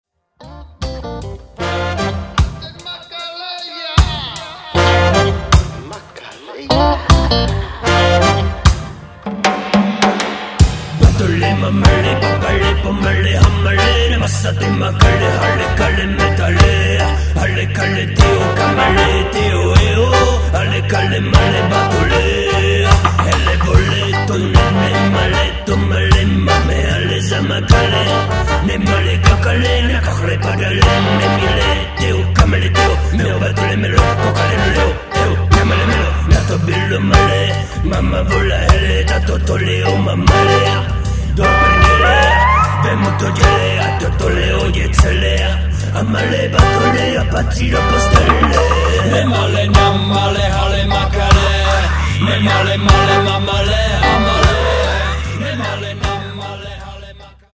rap blues